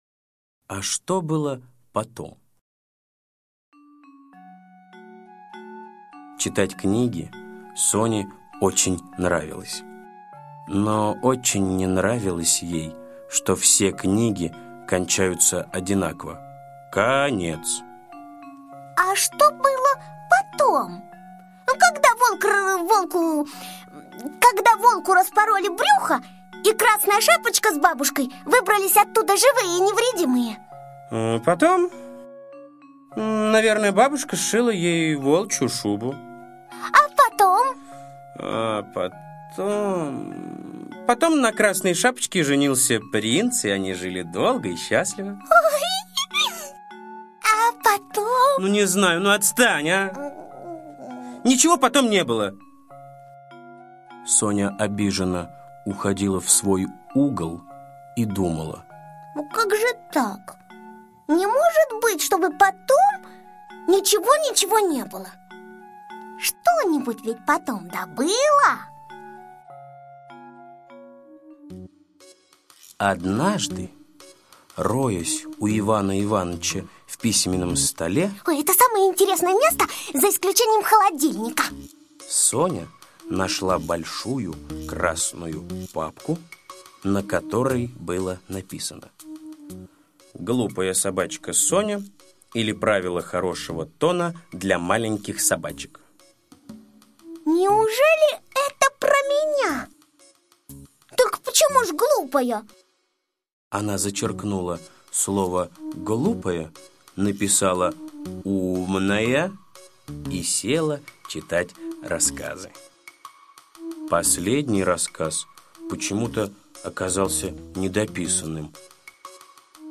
А что было потом? - аудиосказка Усачева А.А. Сказка про собачку Соню, которая всегда хотела знать что было после окончания сказки.